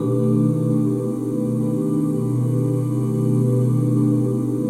OOHBSHARP9.wav